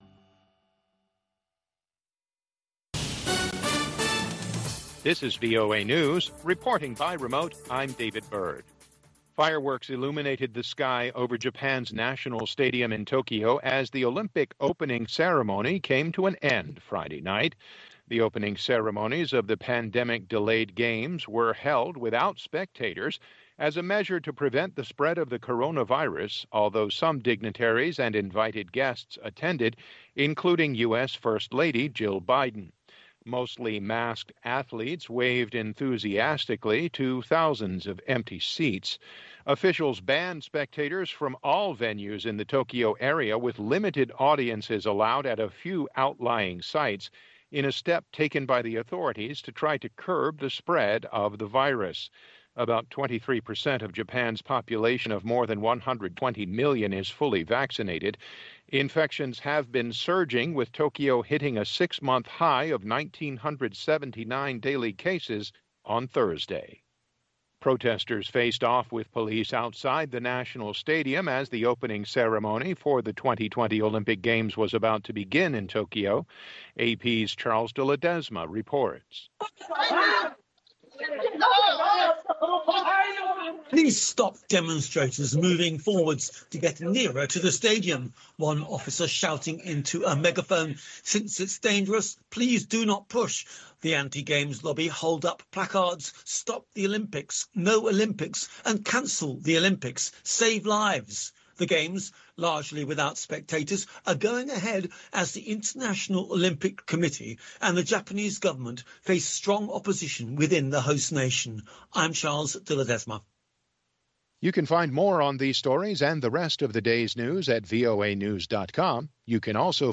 We bring you reports from our correspondents and interviews with newsmakers from across the world.